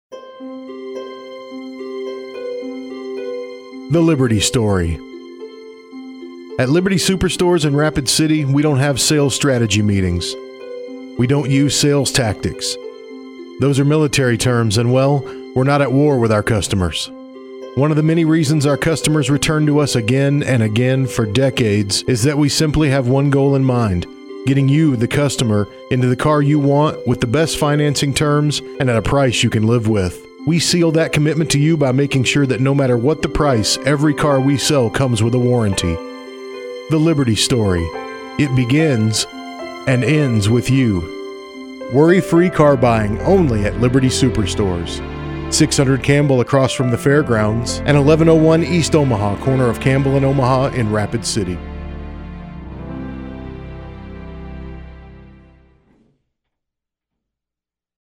Some of the many Radio spots we have created: